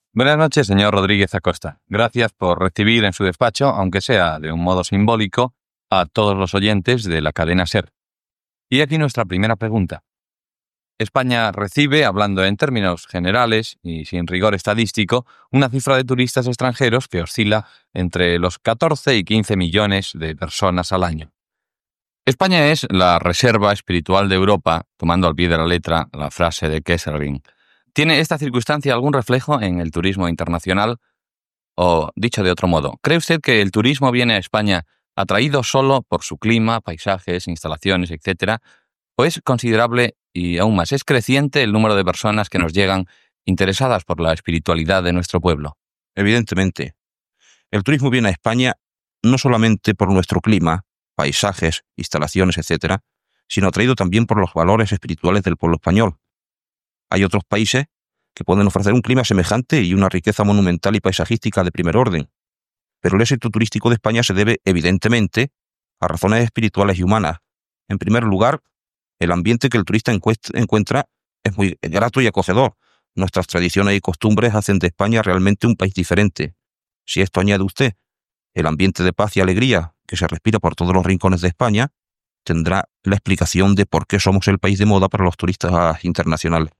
Entrevista al sots director de Turisme Antonio García Rodríguez Acosta sobre el nombre de turistes que visita l'Estat espanyol.